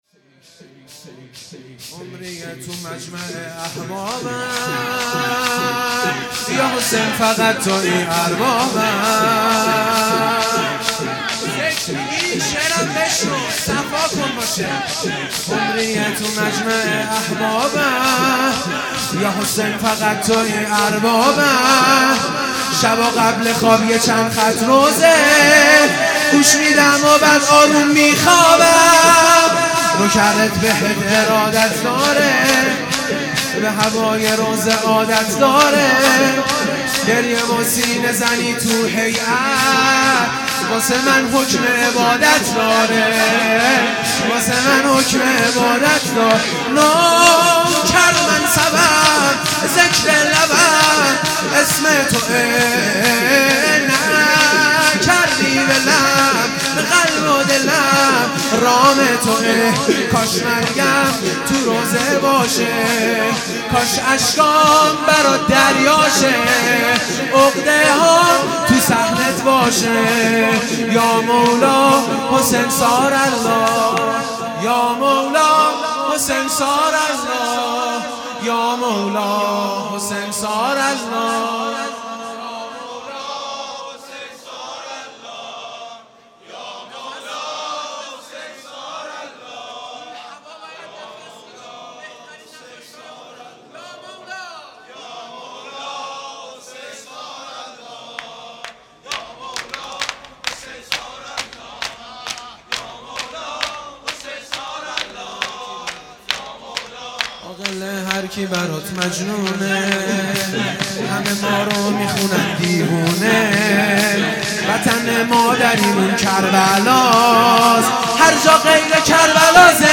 شور
جلسه هفتگی